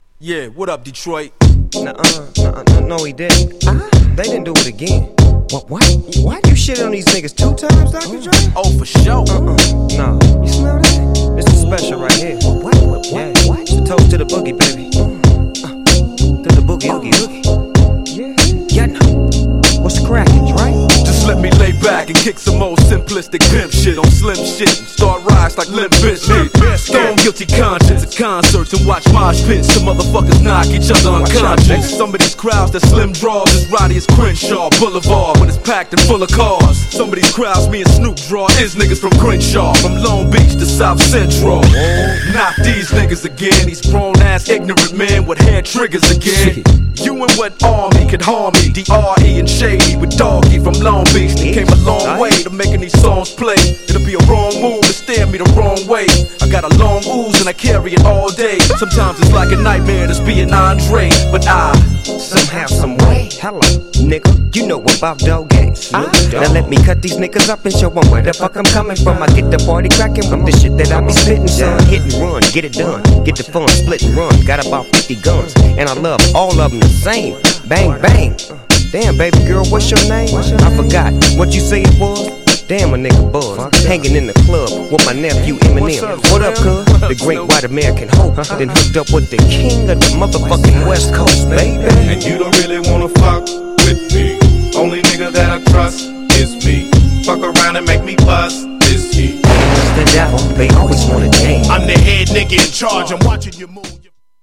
GENRE Hip Hop
BPM 81〜85BPM
フックでR&Bなコーラスがイイ!